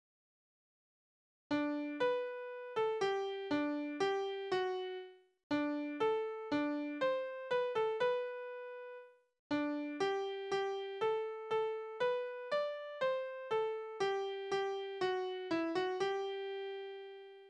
Liebeslieder:
Tonart: G-Dur
Taktart: C (4/4)
Tonumfang: Oktave
Besetzung: vokal